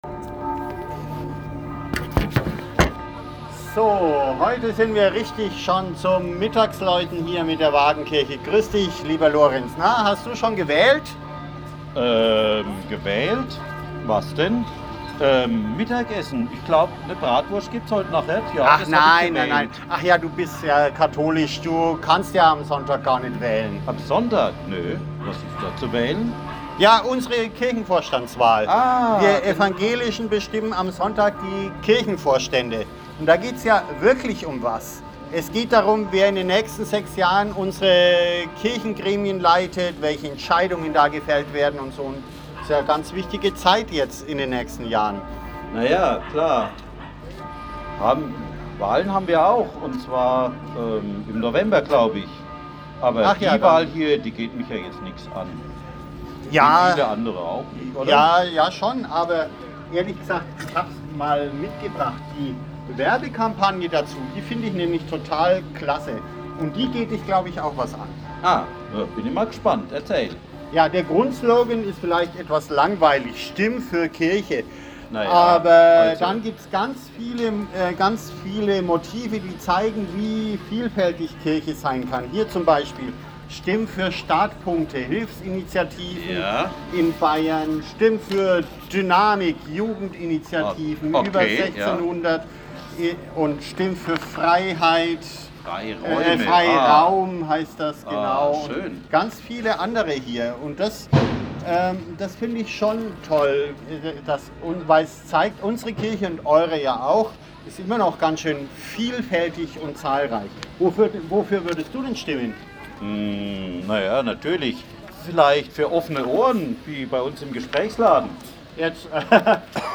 Fußgängerzone. Kurze Impulse zum Nachdenken fürs Wochenende.